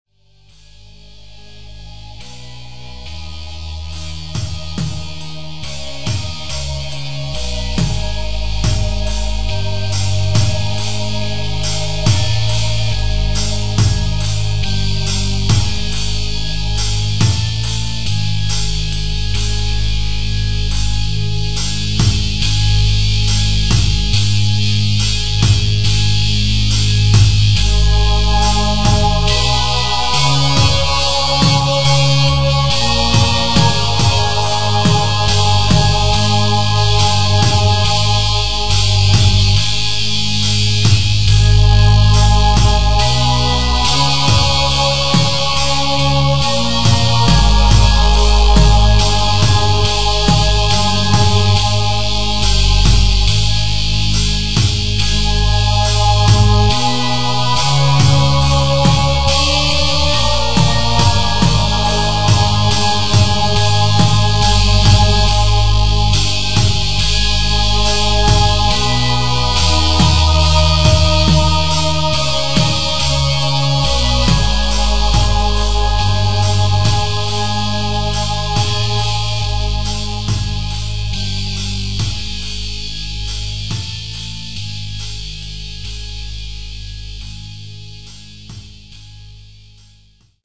style : ambient funerary doom